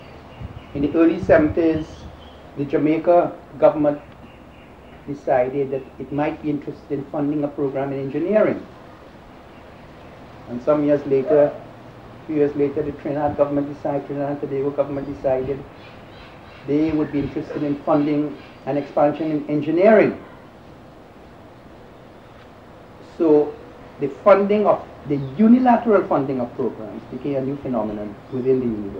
2 audio cassettes